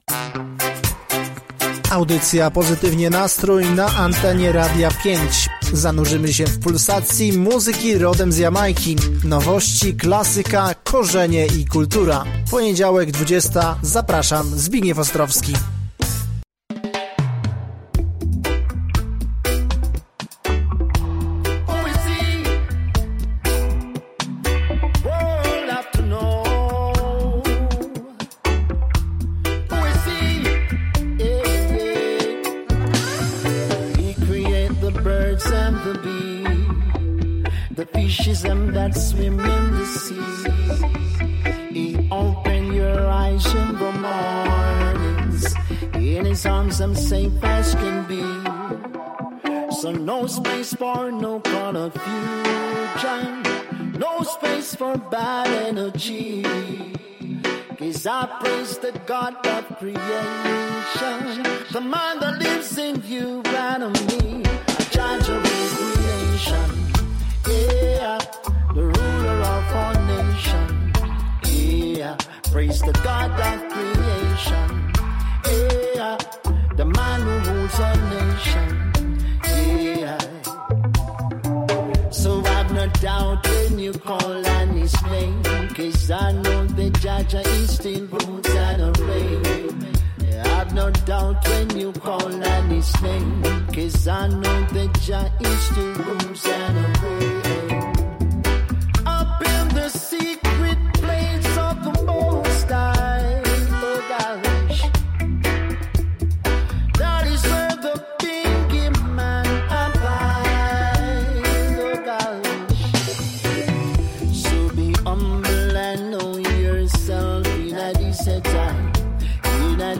W audycji zabrzmiały nowe brzmienia roots i dub
Były też głębsze dubowe klimaty
Na finał poleciały klasyki ska i rocksteady